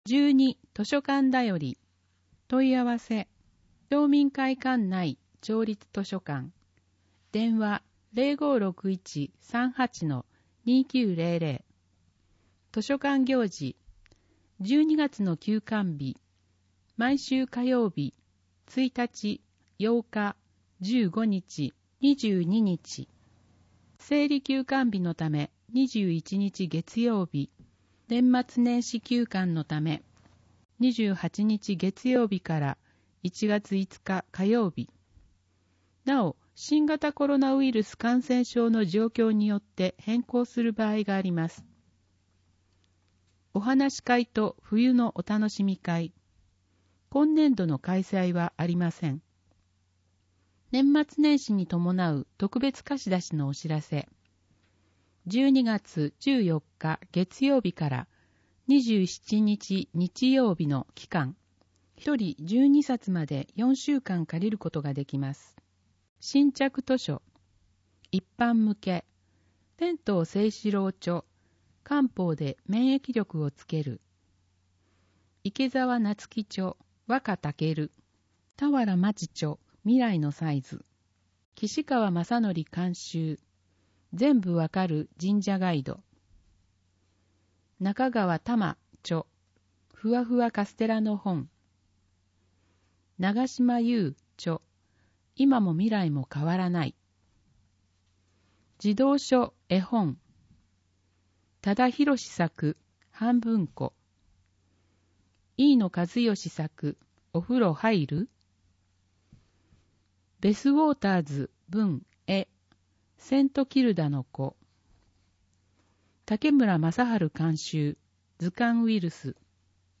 広報とうごう音訳版（2020年12月号）